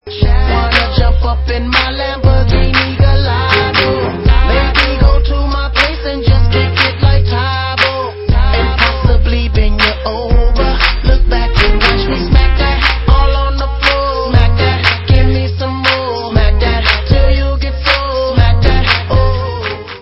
Dance/Hip Hop